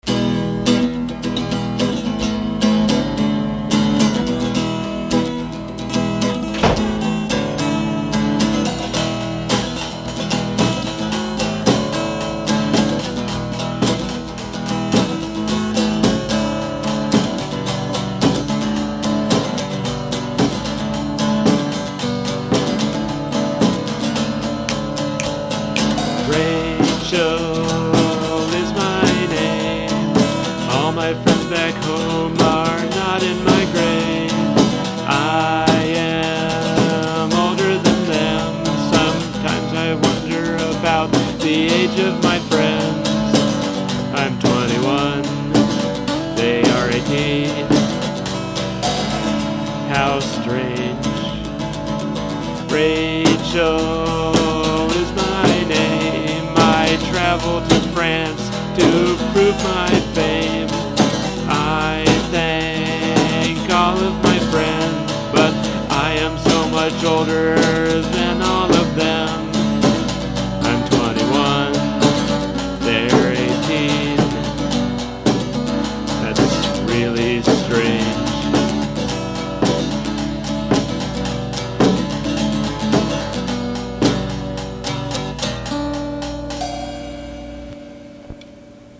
a decent little album featuring a number of fourtrack songs.